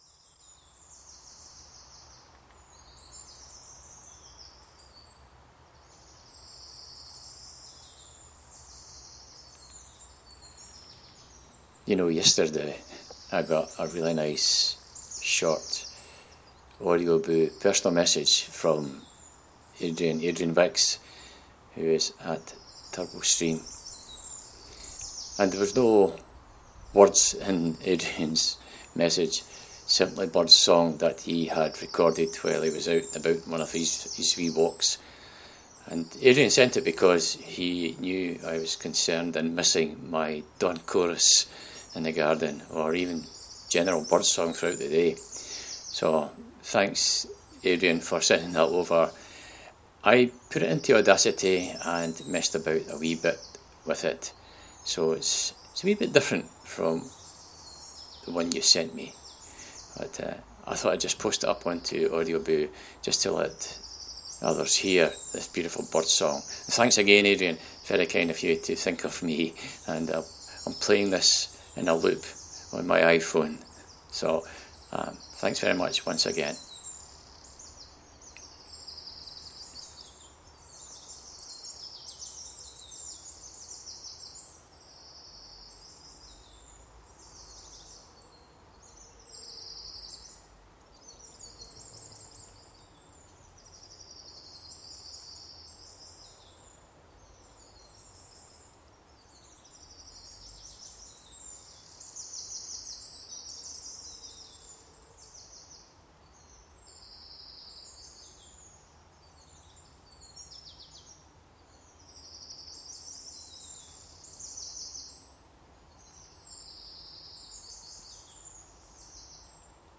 A present of Birdsong